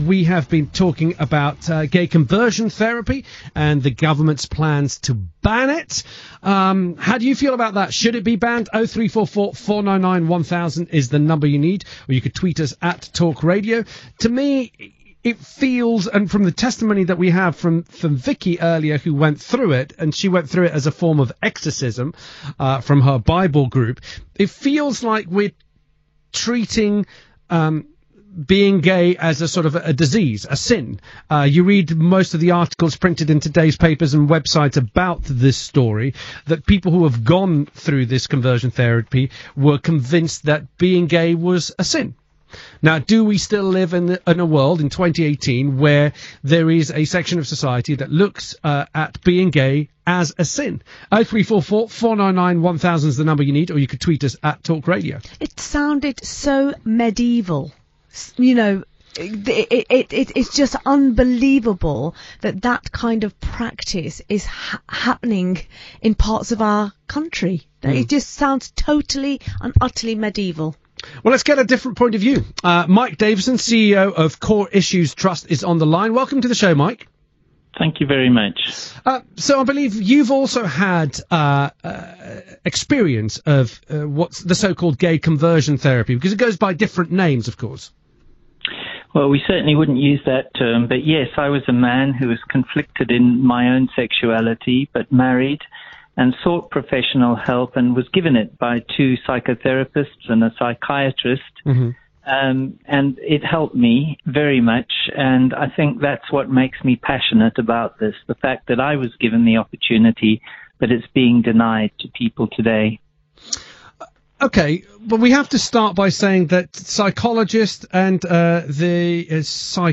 speaks to TalkRadio